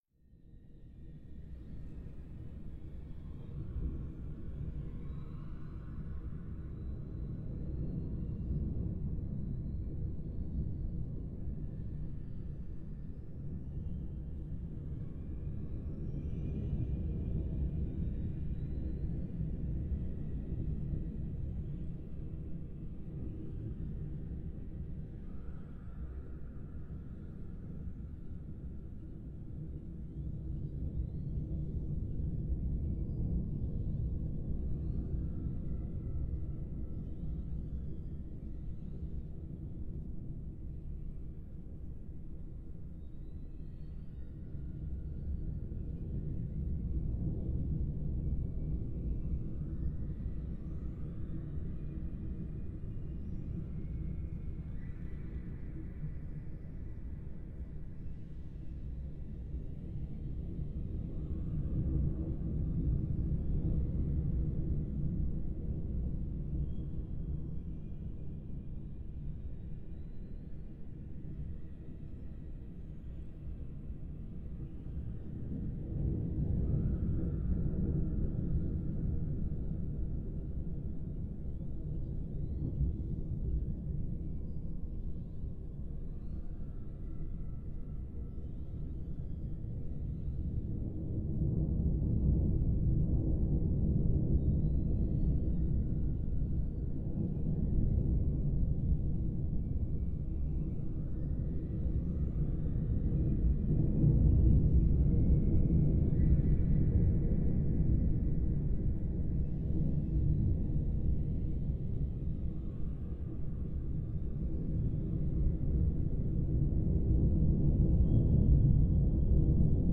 Тихий и умиротворяющий звук подводного мира